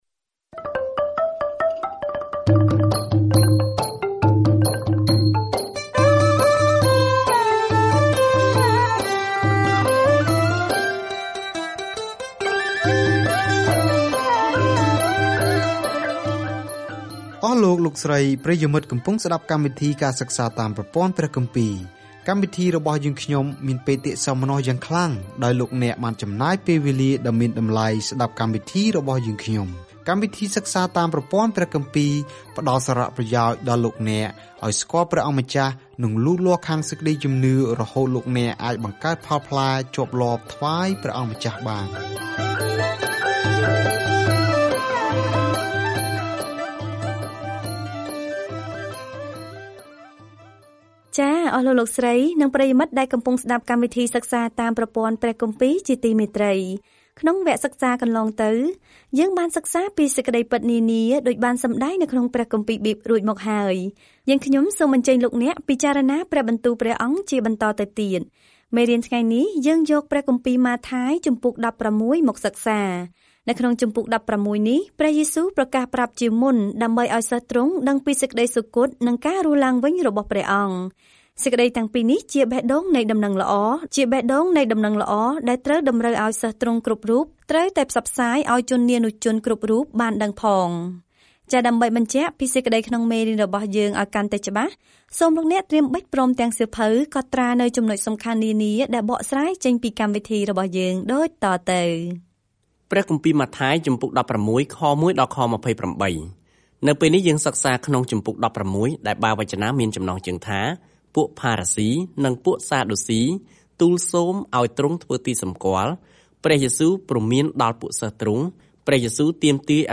ម៉ាថាយបង្ហាញដល់អ្នកអានសាសន៍យូដានូវដំណឹងល្អថា ព្រះយេស៊ូវគឺជាព្រះមេស្ស៊ីរបស់ពួកគេ ដោយបង្ហាញពីរបៀបដែលជីវិត និងកិច្ចបម្រើរបស់ទ្រង់បានបំពេញតាមទំនាយក្នុងព្រះគម្ពីរសញ្ញាចាស់ ។ ការធ្វើដំណើរប្រចាំថ្ងៃតាមម៉ាថាយ ពេលអ្នកស្តាប់ការសិក្សាជាសំឡេង ហើយអានខគម្ពីរដែលជ្រើសរើសពីព្រះបន្ទូលរបស់ព្រះ។